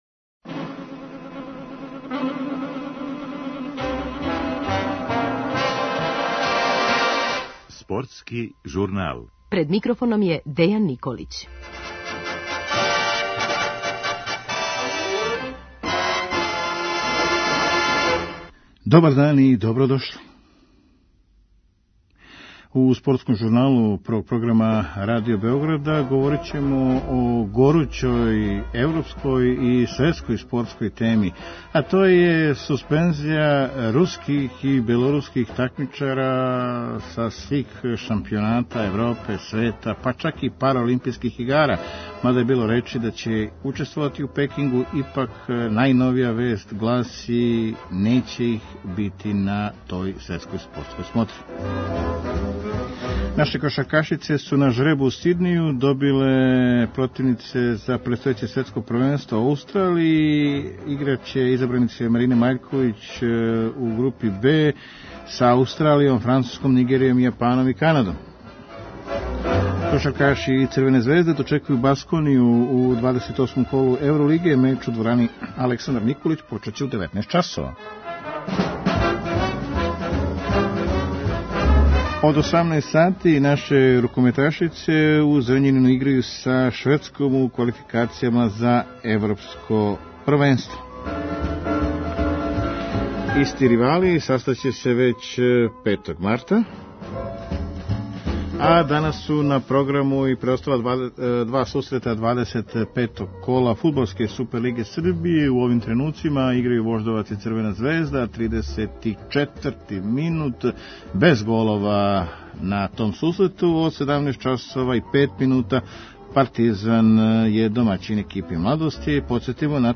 Ексклузивно из Лозане, седишта Међународног олимпијског комитета јавља се члан Извршног одбора МОК-а Ненад Лаловић који образлаже одлуку да се из међународних такмичења суспендују руски и белоруски спортисти.